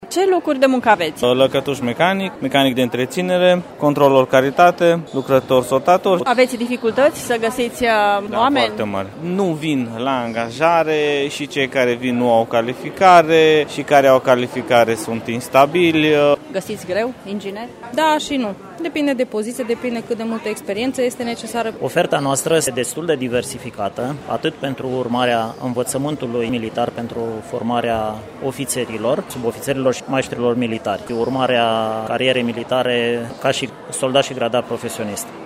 Agenția Județeană pentru Ocuparea Forței de Muncă organizează astăzi, la Sala Polivalentă din Târgu Mureș, Bursa Locurilor de Muncă pentru absolvenți, la care pot participa toți cei aflați în căutarea unui loc de muncă.
Angajatorii se plâng că găsesc greu forță de muncă calificată: